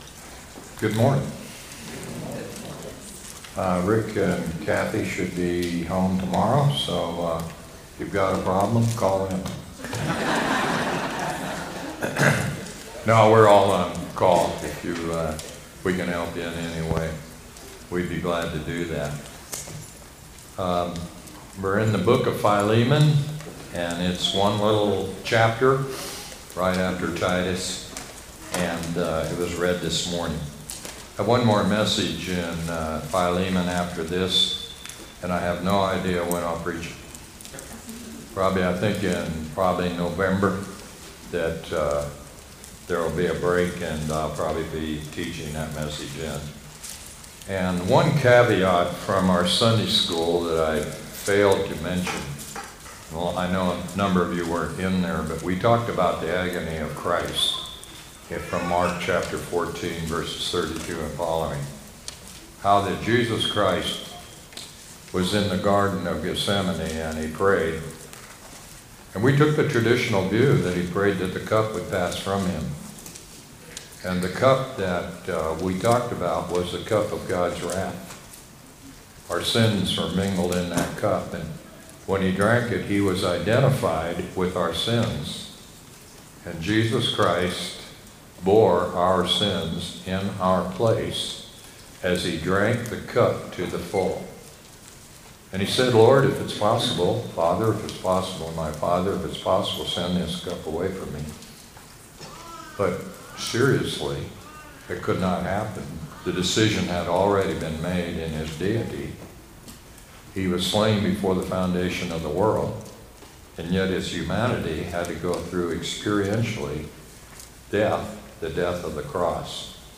sermon-8-10-25.mp3